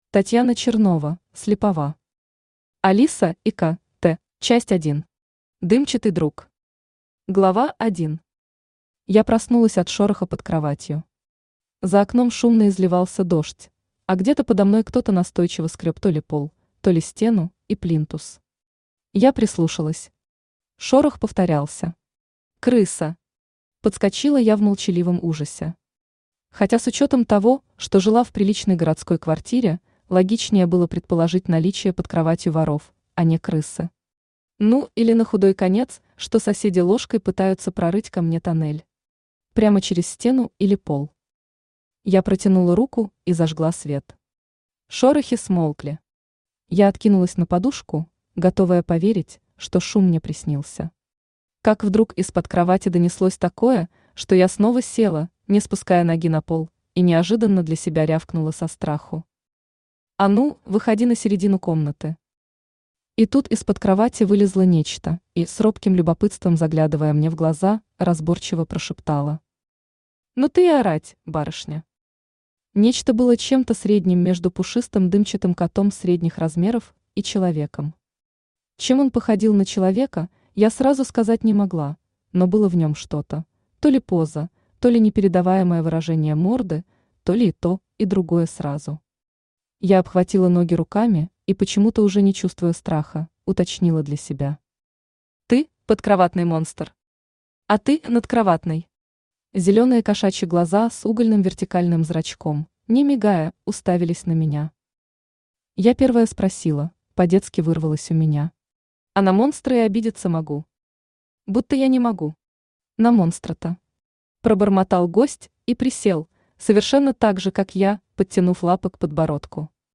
Аудиокнига Алиса и Ко (т) | Библиотека аудиокниг
Aудиокнига Алиса и Ко (т) Автор Татьяна Чернова (Слепова) Читает аудиокнигу Авточтец ЛитРес.